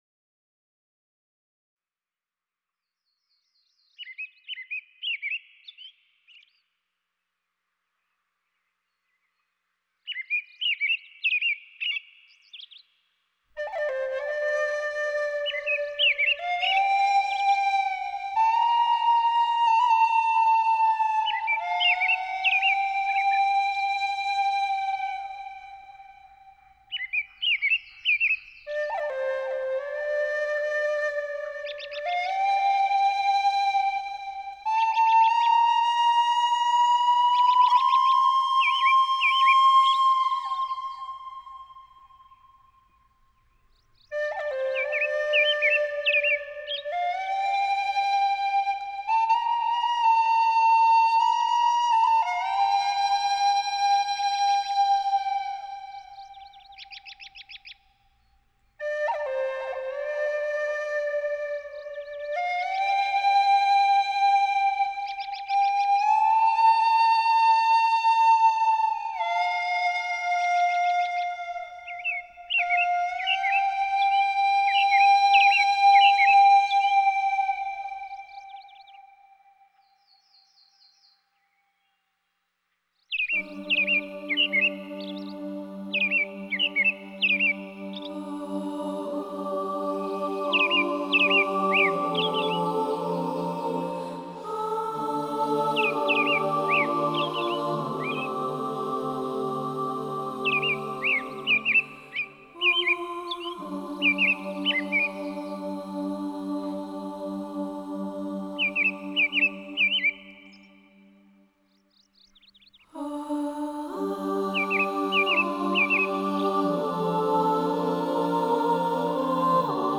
冥想类音乐3
冥想音乐能帮助我们放松身心，还能减轻压力，集中注意力......